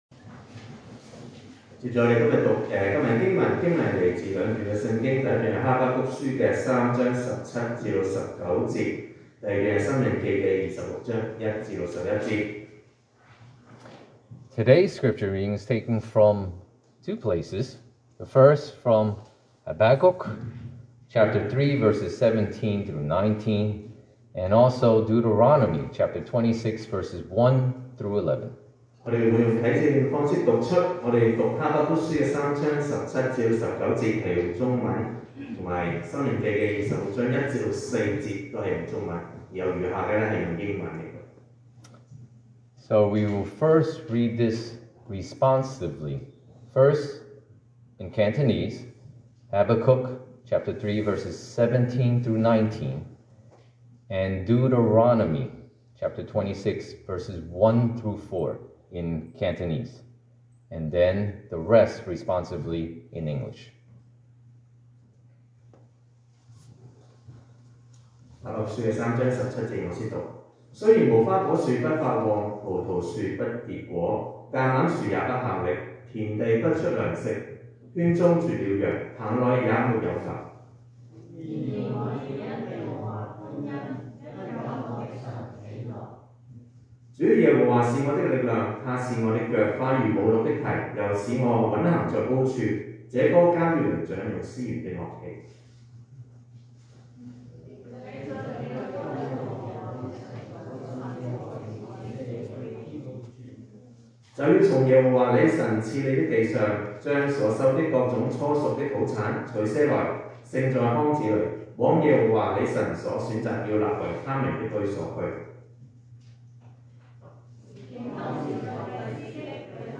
2023 sermon audios
Passage: Habakkuk 3:17-19, Deuteronomy 26:1-11 Service Type: Sunday Morning